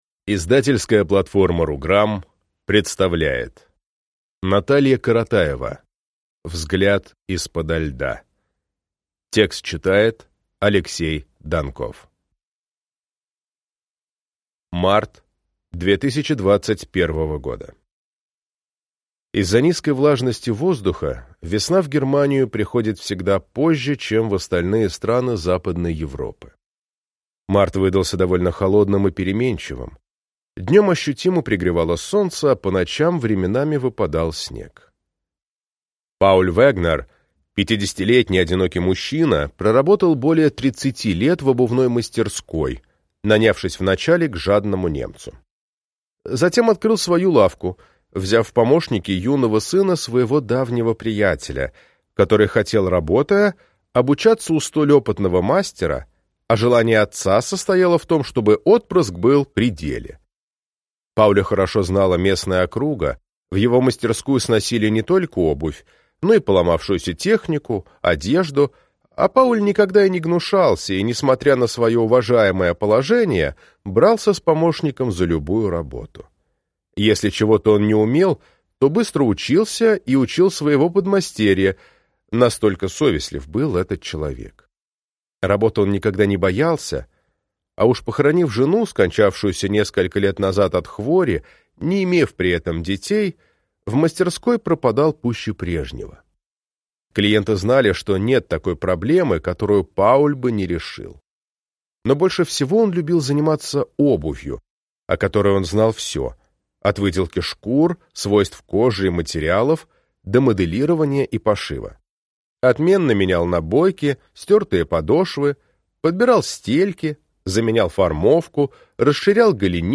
Аудиокнига Взгляд из-подо льда | Библиотека аудиокниг
Прослушать и бесплатно скачать фрагмент аудиокниги